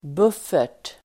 Uttal: [b'uf:er_t]